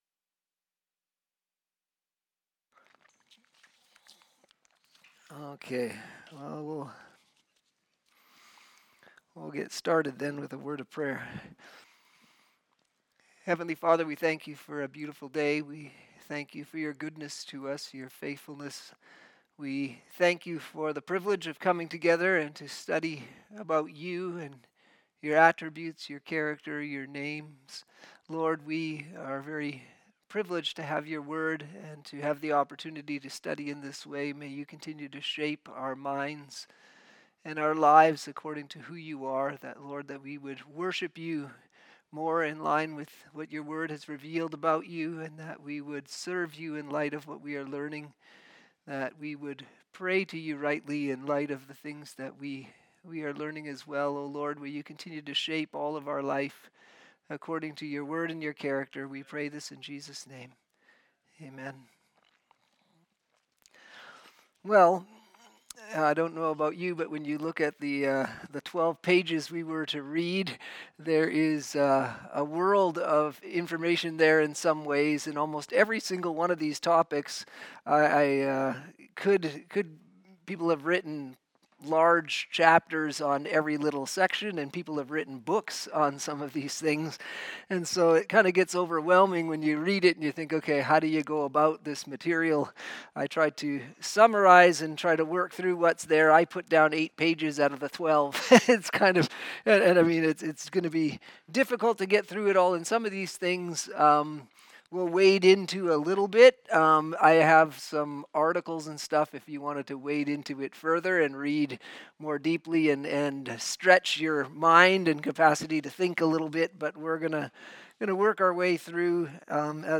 Sermons
Systematic Theology Class - Teaching